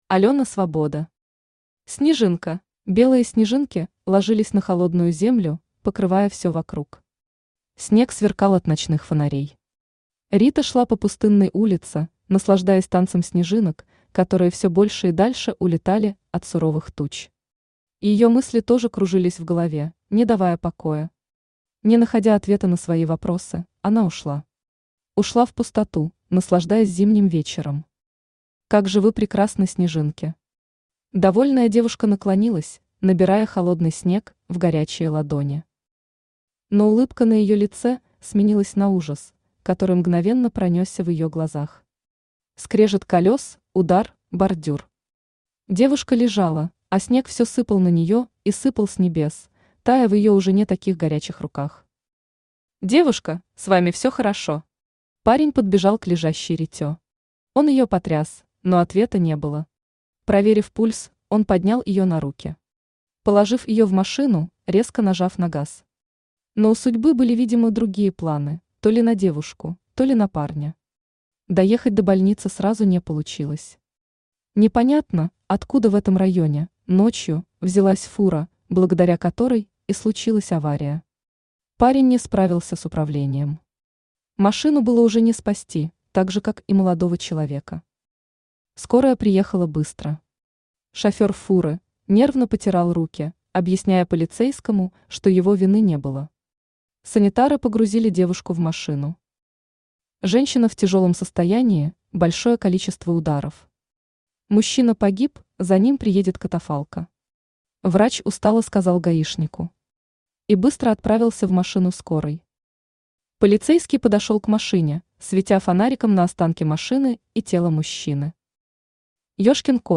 Аудиокнига Снежинка | Библиотека аудиокниг
Aудиокнига Снежинка Автор Алена Евгеньевна Свобода Читает аудиокнигу Авточтец ЛитРес.